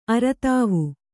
♪ aratāvu